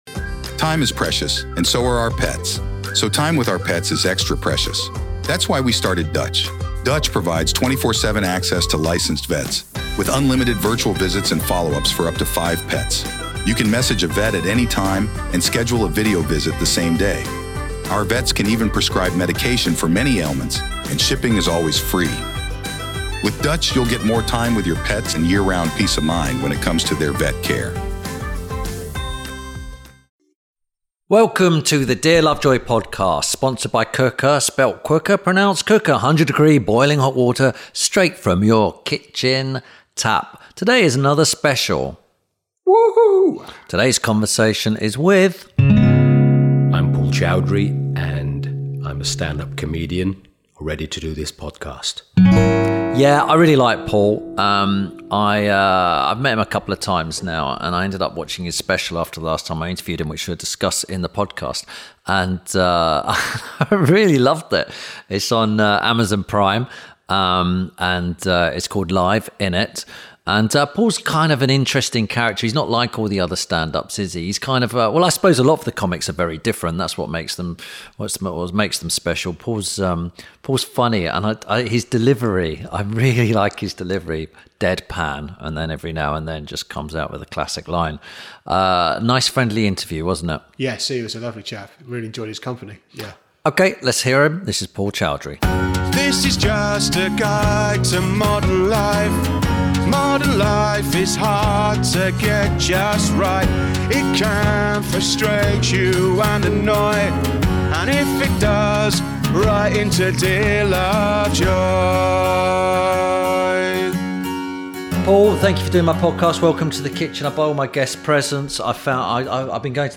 This week Tim Lovejoy talks to comedian Paul Chowdhry. Tim and Paul discuss his new Amazon Prime special ‘Live Innit’, comedy with Dave Chappelle and the difference between comedy in the US and the UK.